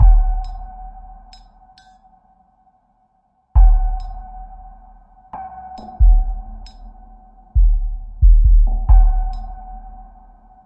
Ambient Groove " Ambient Groove 002
描述：为环境音乐和世界节奏制作。完美的基础节拍。
Tag: 环境 沟槽 回路